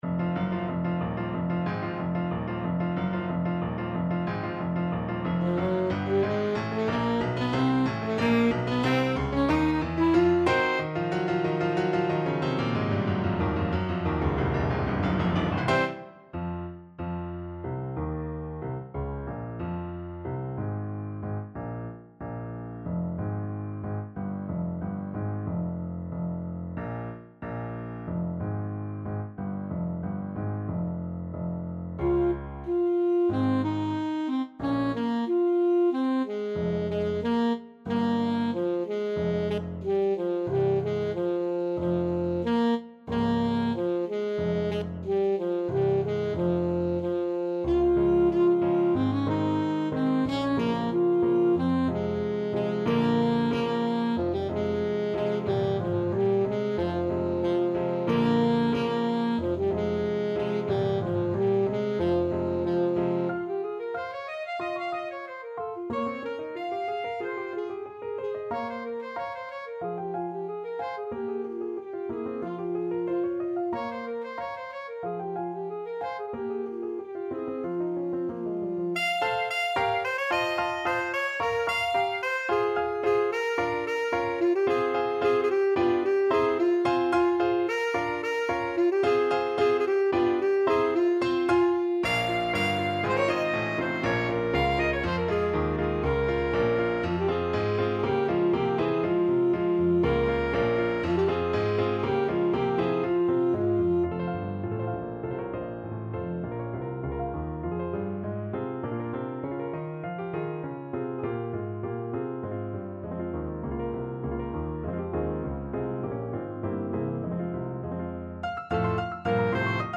Alto Saxophone
2/4 (View more 2/4 Music)
Allegro =92 (View more music marked Allegro)
Classical (View more Classical Saxophone Music)